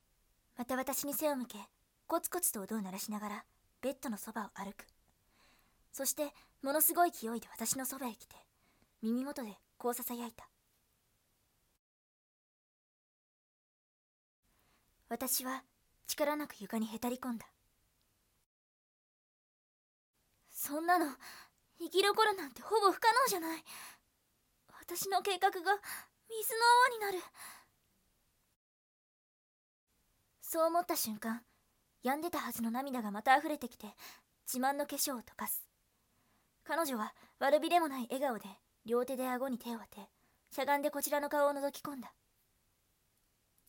40 ナレ